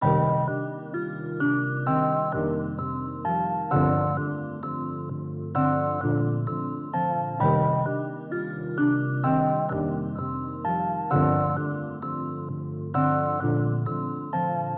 Tag: 130 bpm Hip Hop Loops Bells Loops 2.49 MB wav Key : Unknown